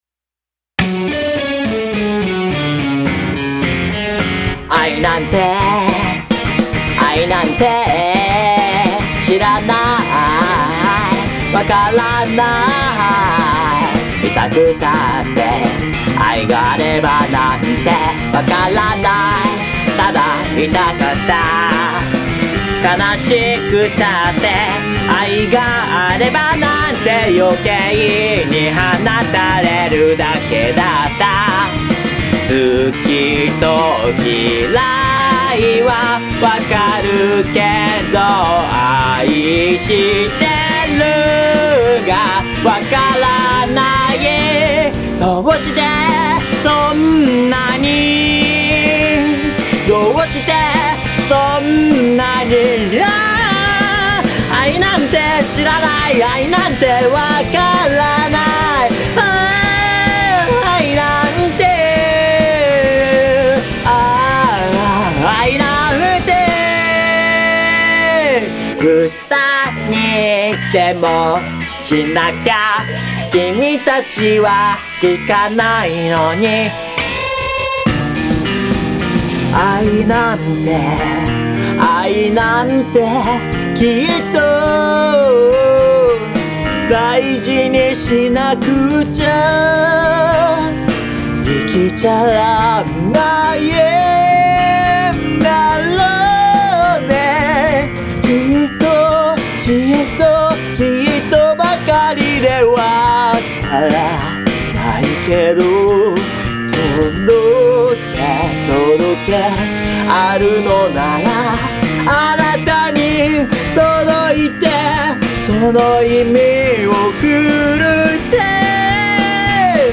打ち込みと歌録りで曲にしてみました。
↓IFのバンドバージョン（ベースとドラムを追加）。
変拍子にしてしまいました。
フラットが出まくる偏屈なマイナーメロディにしました。
IWishYouWishIAmHere_band.mp3